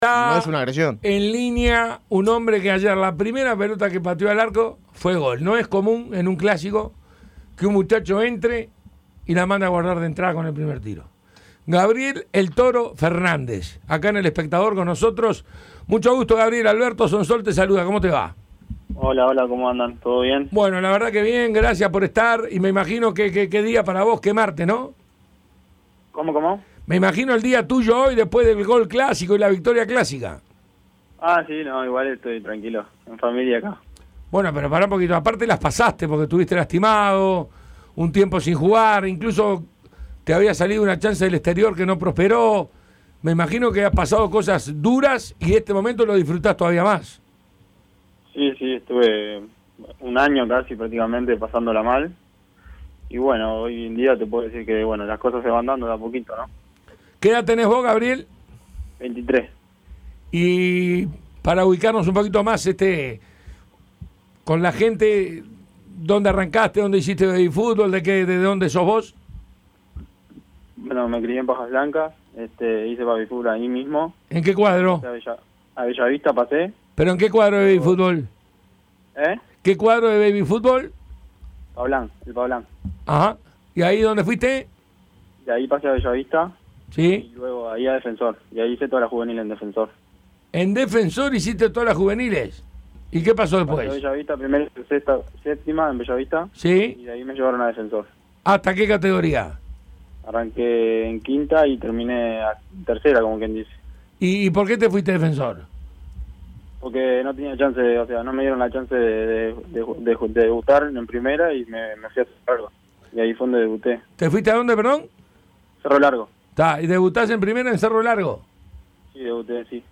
El delantero de Peñarol, Gabriel "Toro" Fernández, debutó con gol en un clásico. Al respecto, habló con el panel de Tuya y Mía sobre su presente, su futuro y su pasado, con algunas curiosidades que llamaron la atención. Entrevista completa.